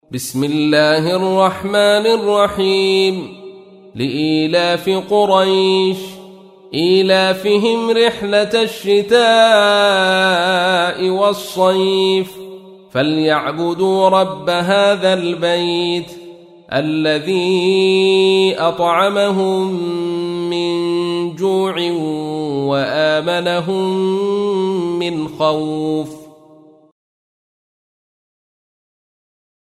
تحميل : 106. سورة قريش / القارئ عبد الرشيد صوفي / القرآن الكريم / موقع يا حسين